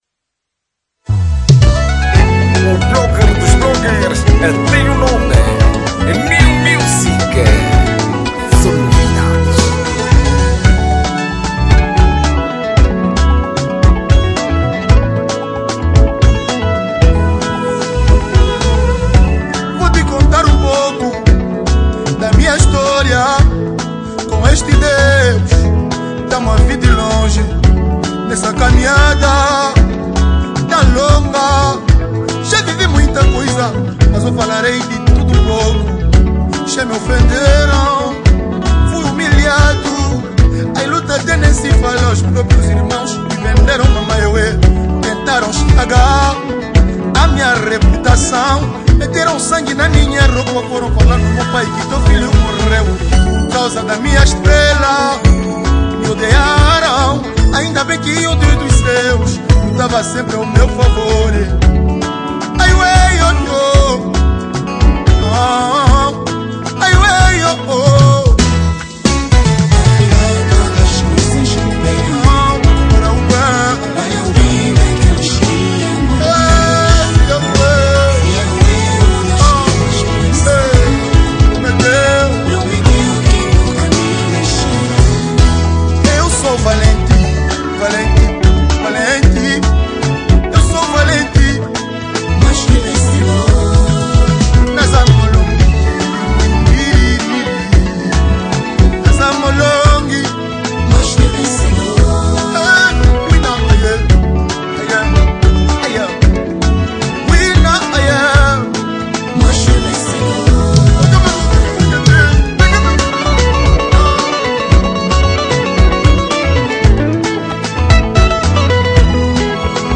Gênero: Gospel